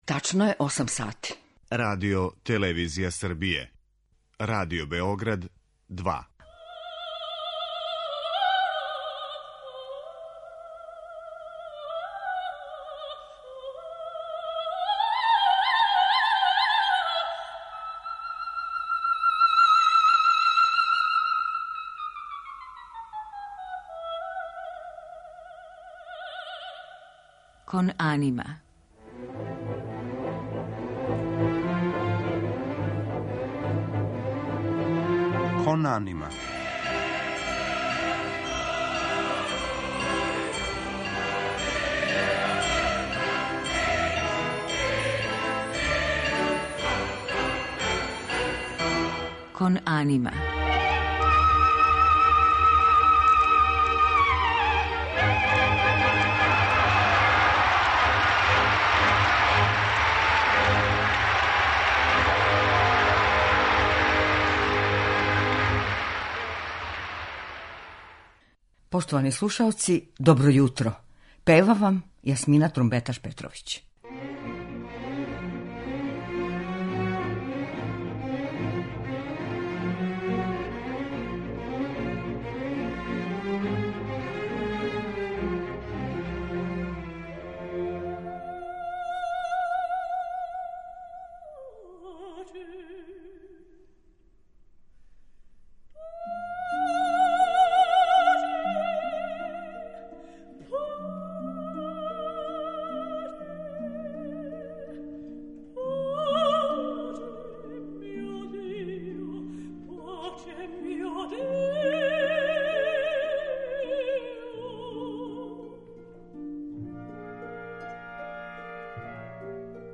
У музичком делу емитоваћемо арије из опера Вердија, Пучинија и Чилеа, у њеном тумачењу.